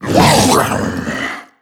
c_agrunt_hit2.wav